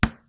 Wood 3.wav